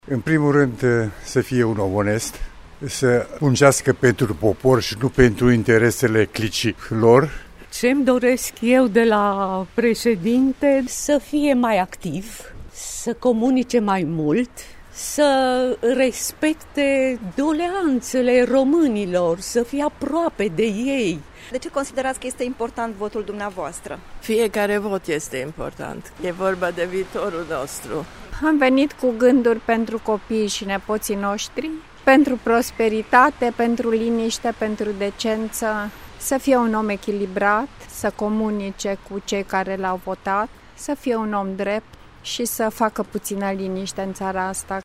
VOX-alegeri-timisoara.mp3